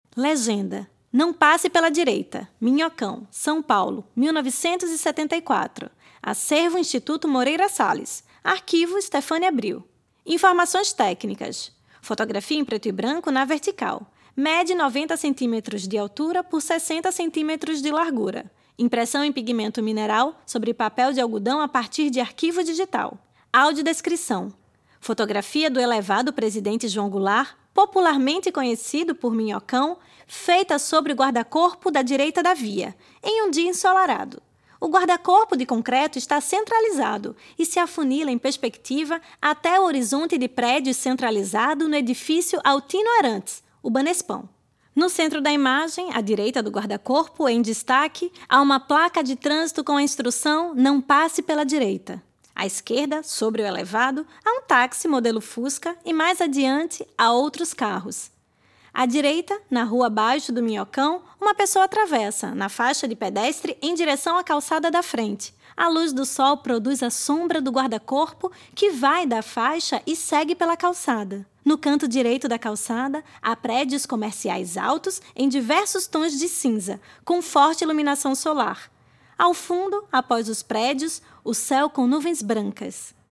Faixa 1 - Audiodescrição